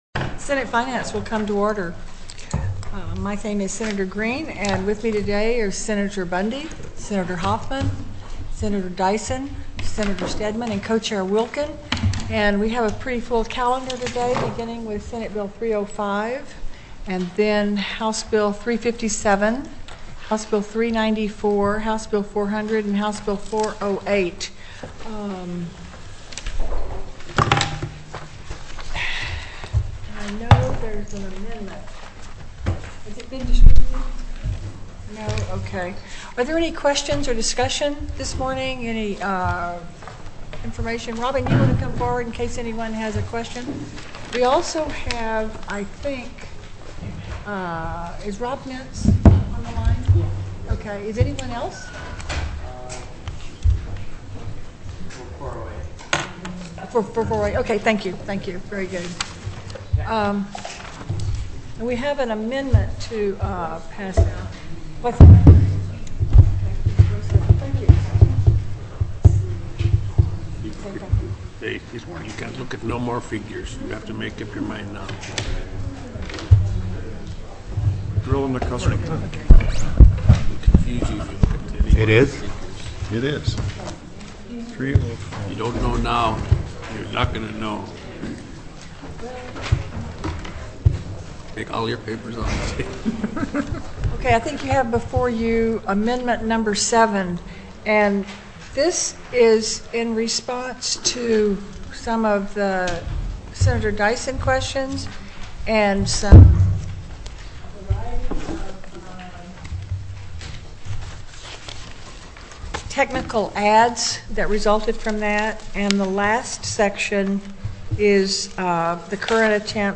04/22/2006 09:00 AM Senate FINANCE